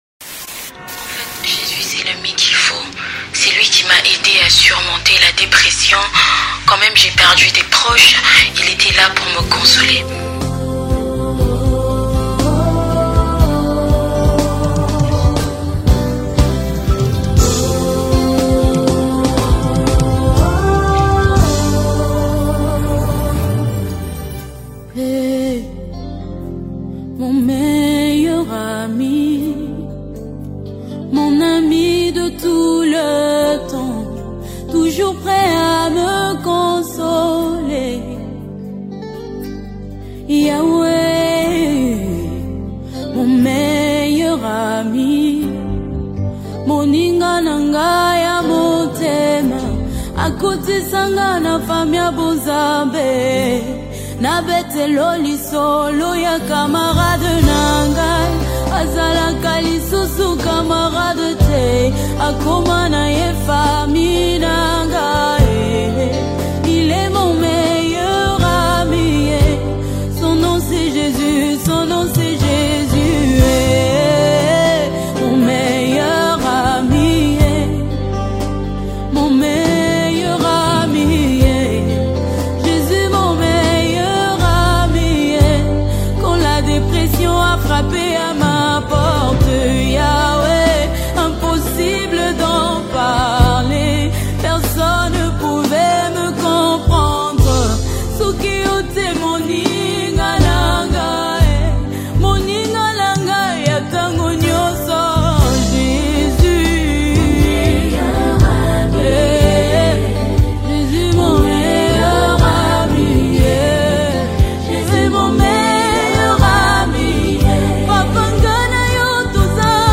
Gospel 2021